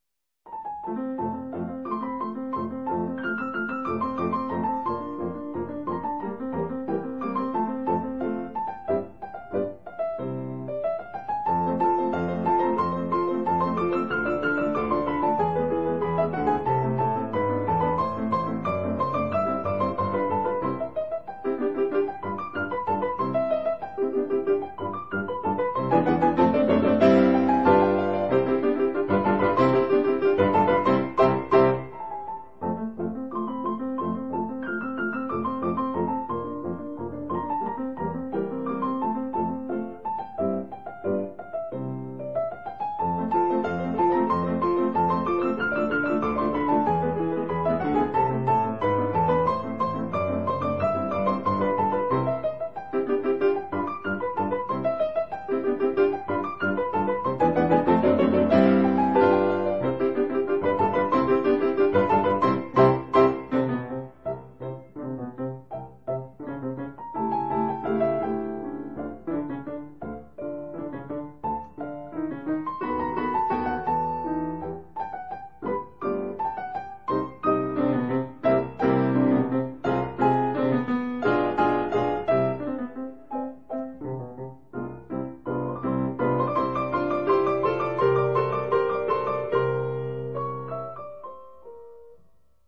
鋼琴四手聯彈